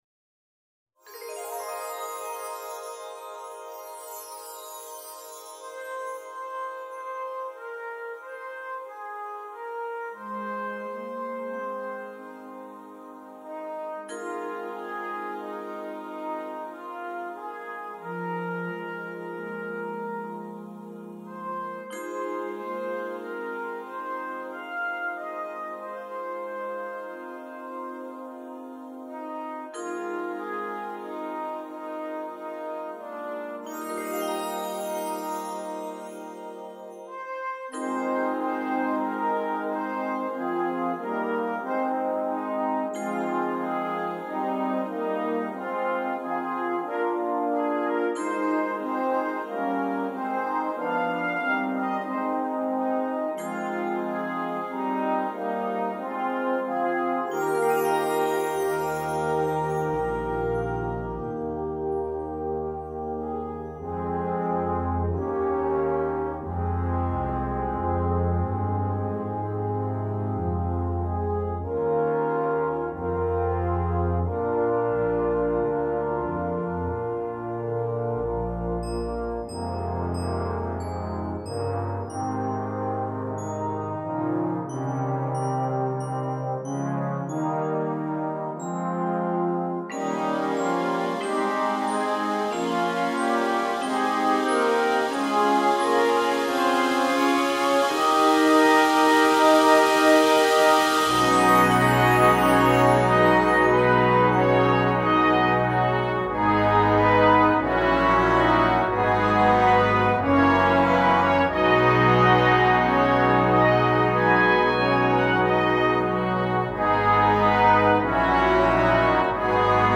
Besetzung: Brass Band